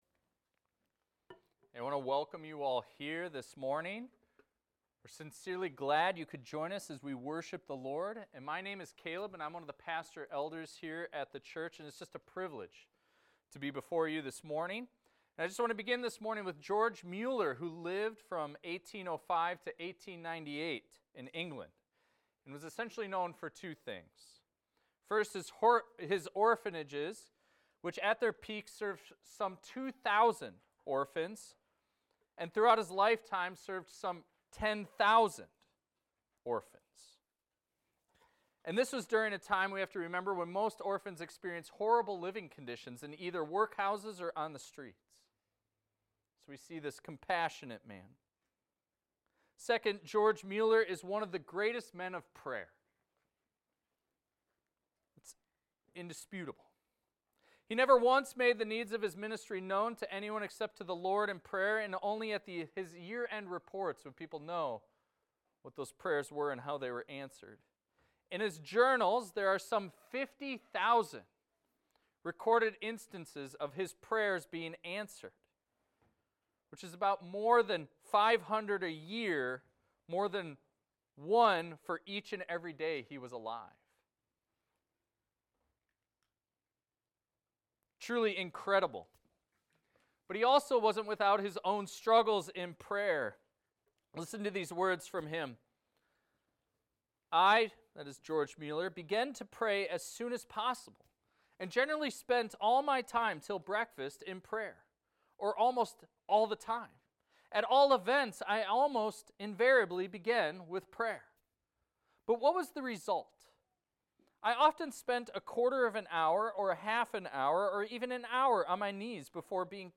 This is a recording of a sermon titled, "Prayerful Saints."